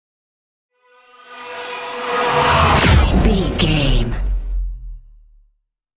Sci fi super speed vehicle whoosh
Sound Effects
dark
futuristic
intense
whoosh